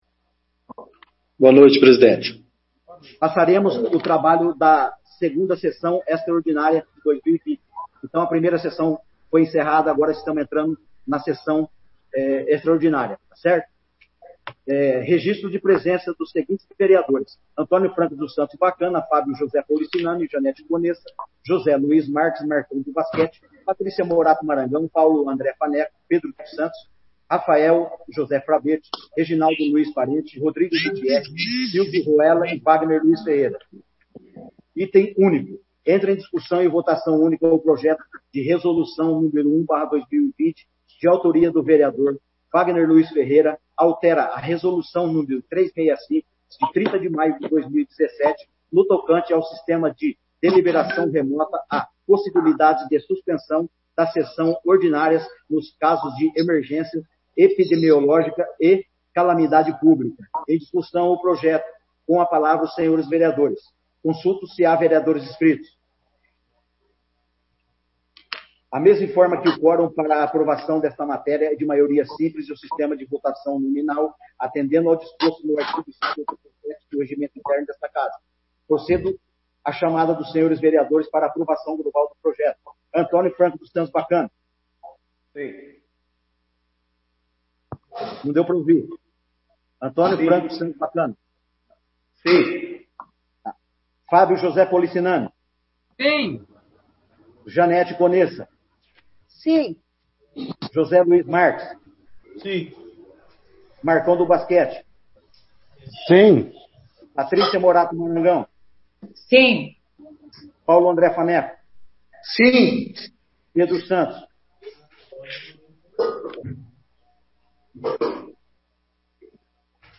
2ª Sessão Extraordinária de 2020